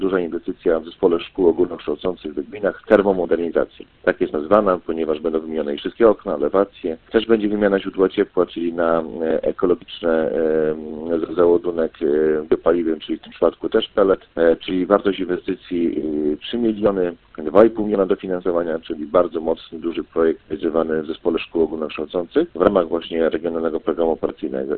– Pierwszą z inwestycji będzie przebudowa kotłowni oraz montaż instalacji paneli fotowoltaicznych w budynku Urzędu Gminy oraz Gminnego Ośrodka Kultury – mówi Radosław Król, wójt gminy Wydminy.